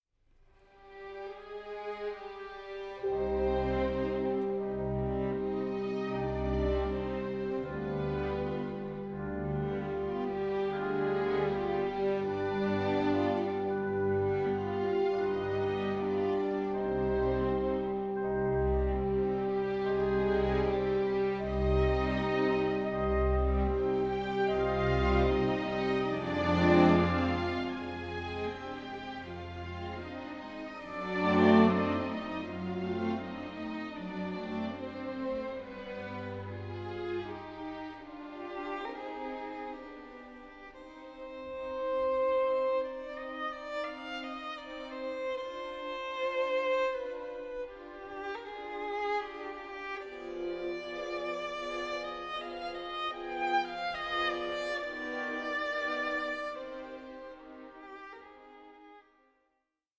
Adagio for Violin & Orchestra in E Major